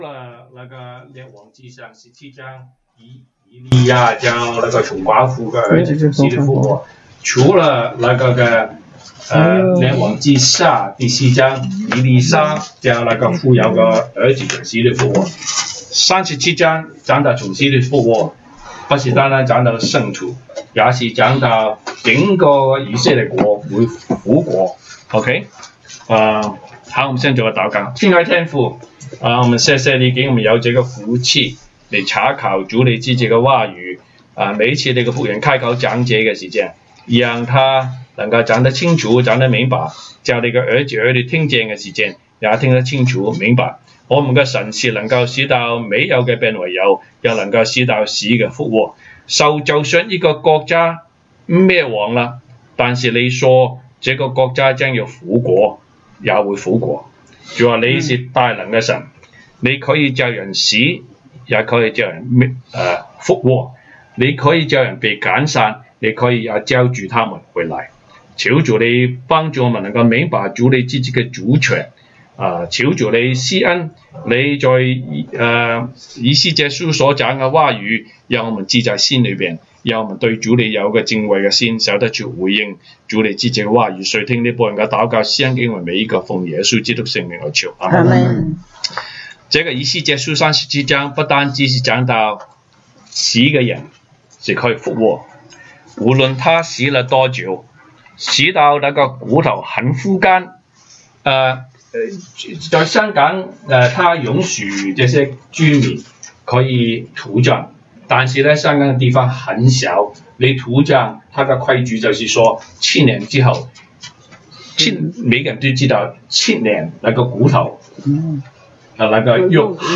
週一國語研經
以西結書 Ezekiel 37:1-28 Service Type: 東北堂證道 (粵語) North Side (First Church) « 週一國語研經